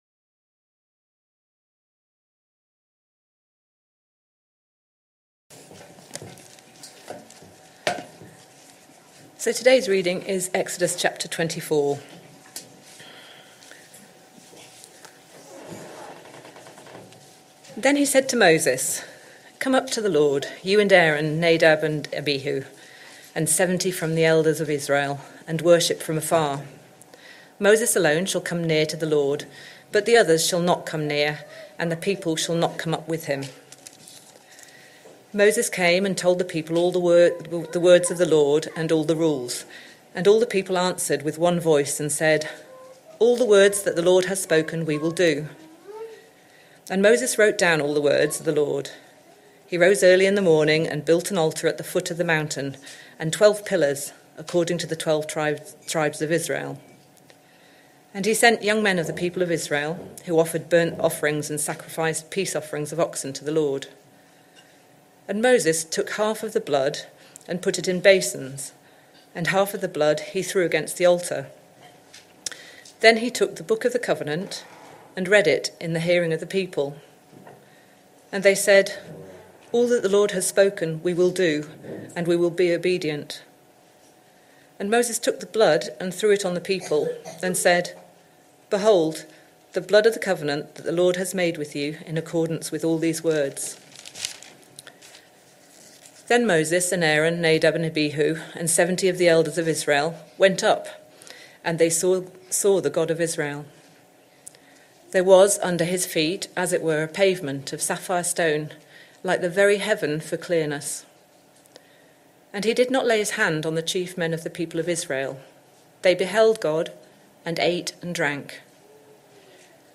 Sunday Morning Service Sunday 13th July 2025 Speaker